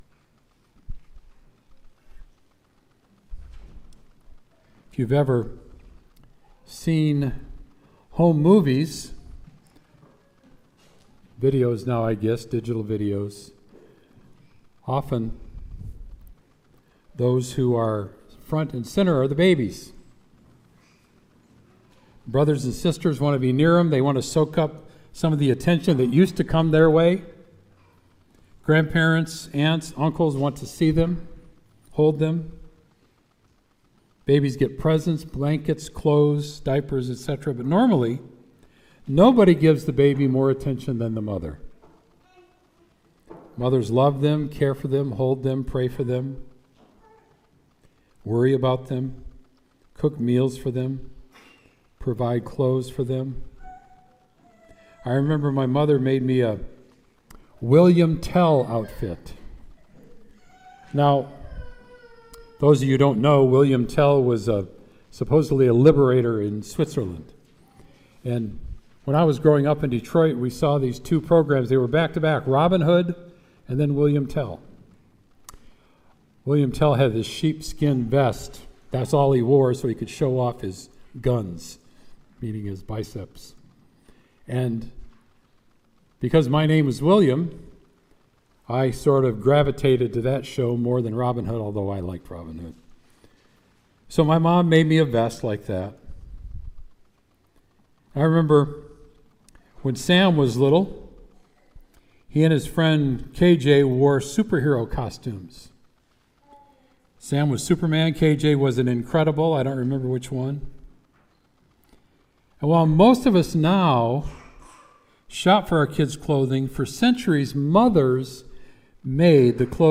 Sermon “Jesus is Crucified”